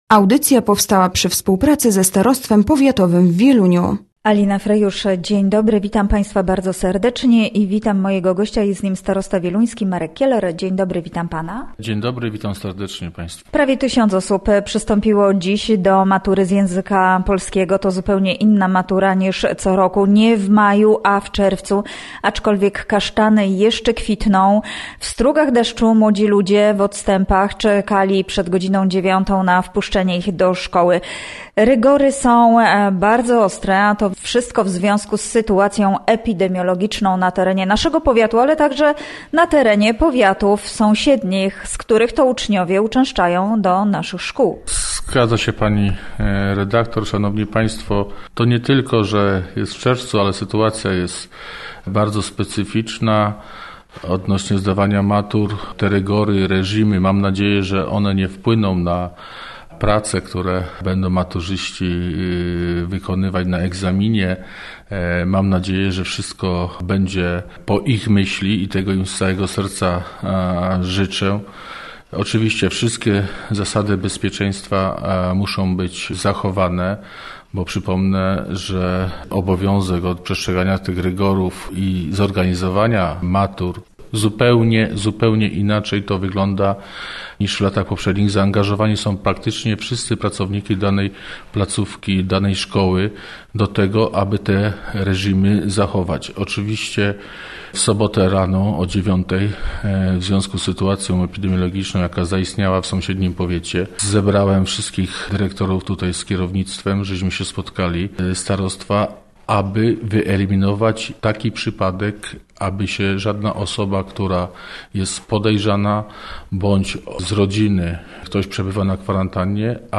Gościem Radia ZW był Marek Kieler, starosta wieluński